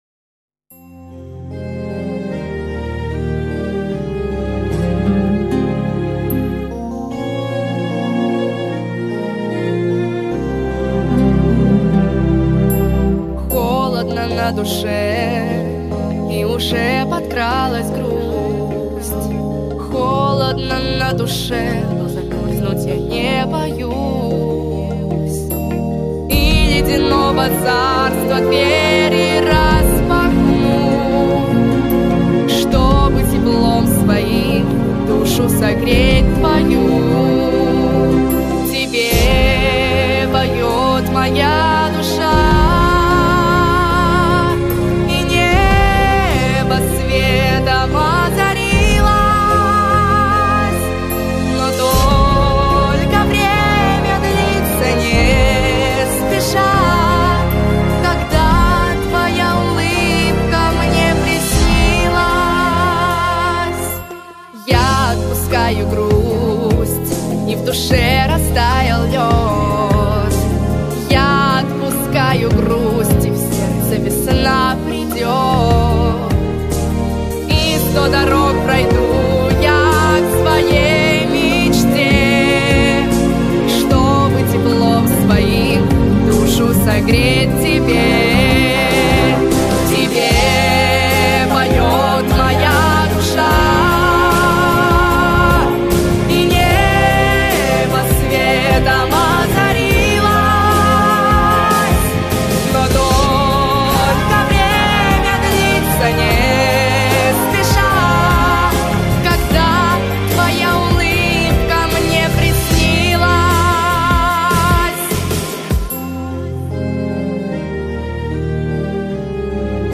• Категория: Детские песни
подростковые песни